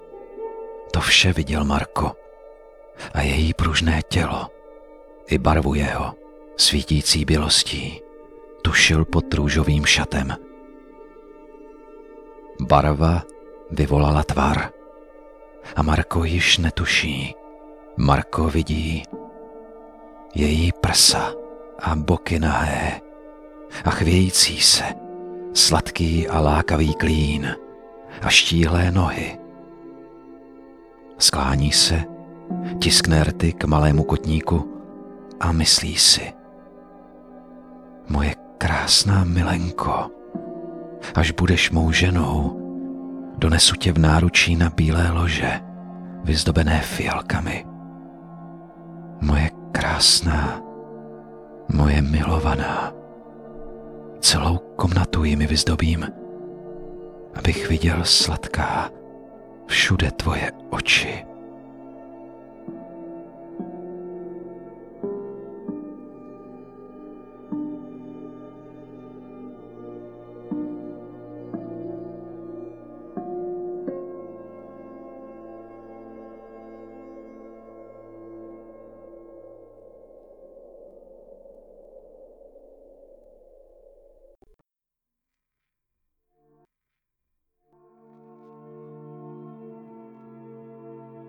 Vzhledem k sedmileté praxi v divadelní činohře jsem schopen s hlasem pracovat a přizpůsobit jej Vašim přáním a požadavkům.
Ačkoliv jsem krom výše zmíněného natočil i celou řadu produktových videí, voiceoverů, pracoval jsem mimo jiné i pro Lenovo, Office Depot a další, primárně se specializuji na čtený, mluvený projev (dabing, dokumentární filmy, audioknihy).